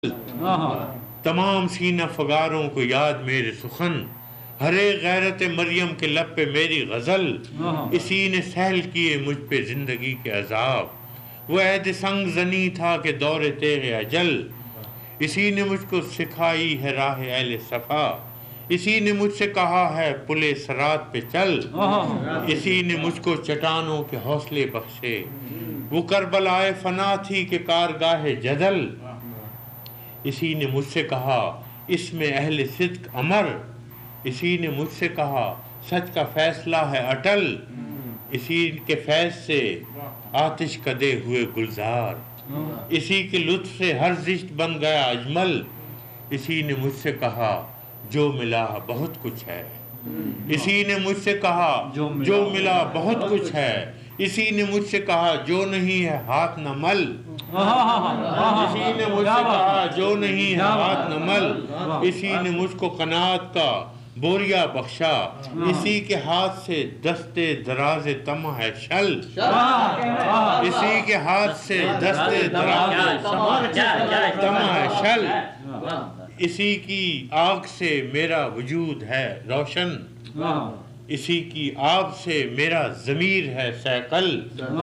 It is posted on youtube as a recitation by faraz at a private gathering.
af-qaseeda-qalam-32-40-dars-e-qalam-faraz.mp3